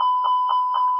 add a proper CRC sound